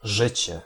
Ääntäminen
IPA: [ˈʐɨt͡ɕɛ]